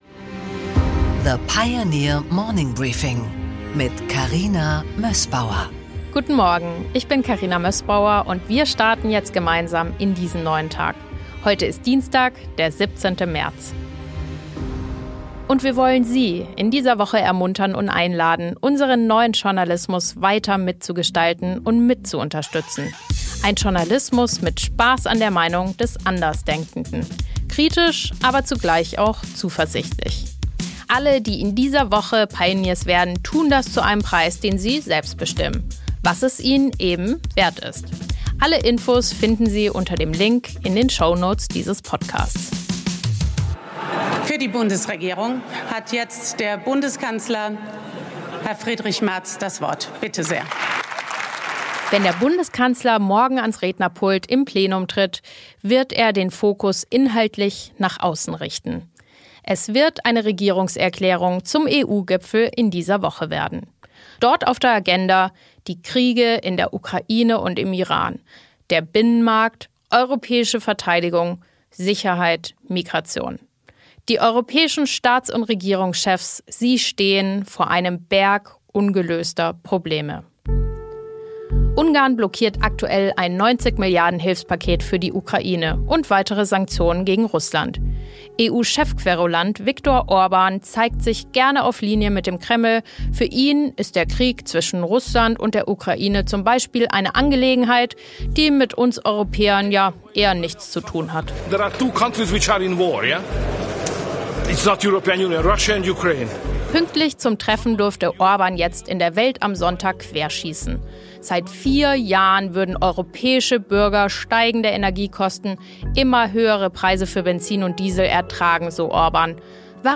Interview mit Prof. Wolfgang Ischinger